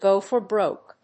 アクセントgó for bróke